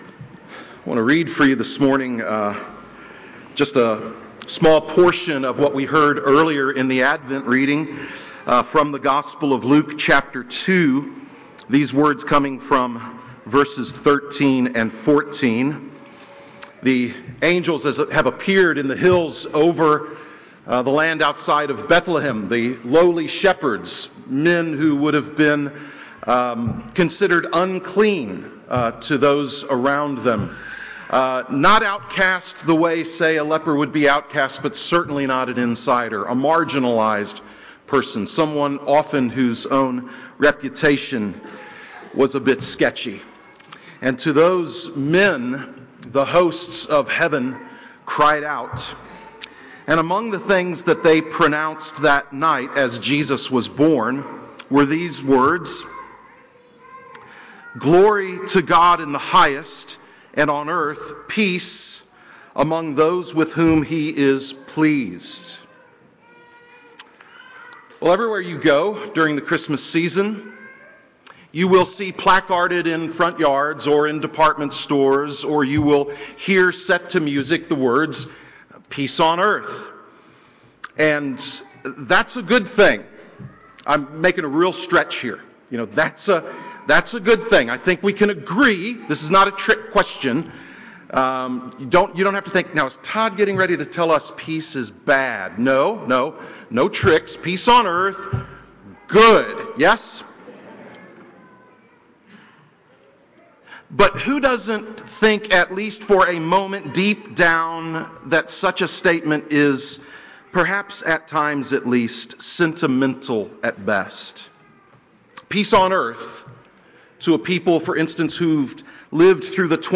Christmas Eve Service 2025